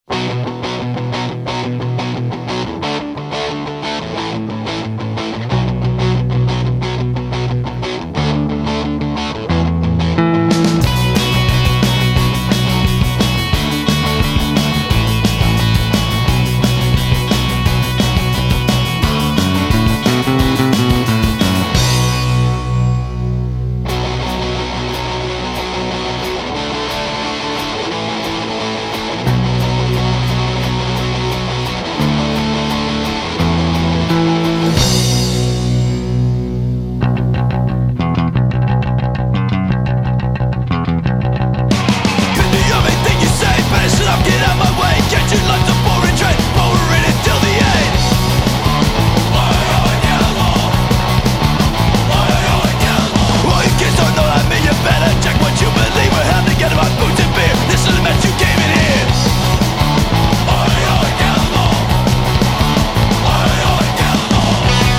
South Jersey punk trio